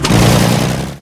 tank-engine-load-reverse-2.ogg